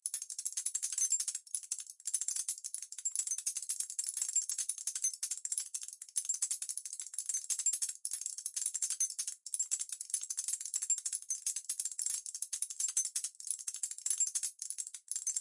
金属裂纹
描述：金属噼啪声是由经过大量加工的样品制成的
Tag: 噼啪 纹理 金属 合成